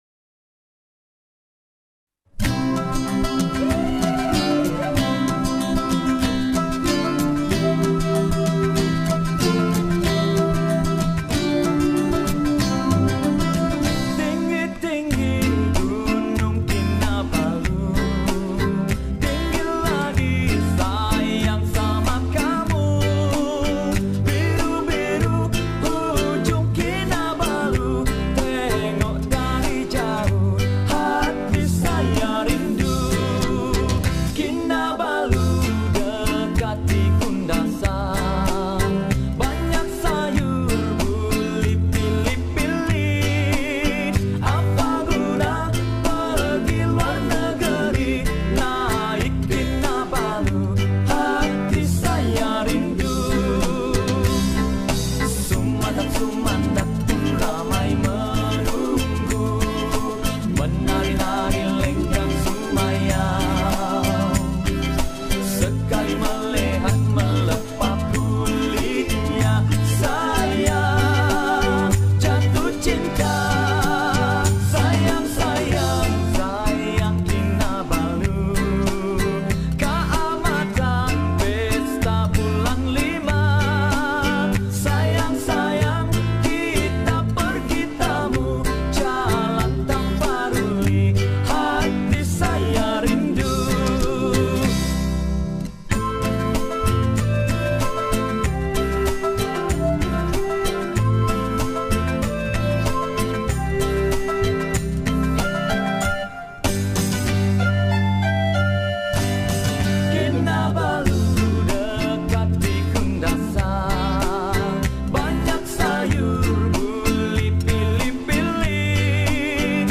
Kadazan Song
Skor Angklung